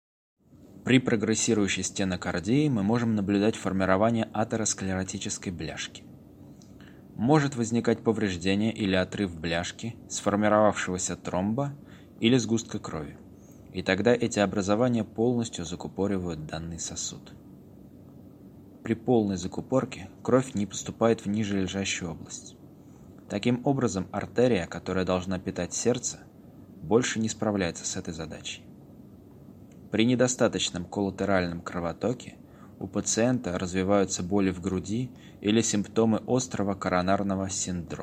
Перевод и озвучка на русском (медицина)